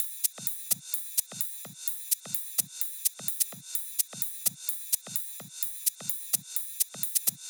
VDE 128BPM Close Drums 3.wav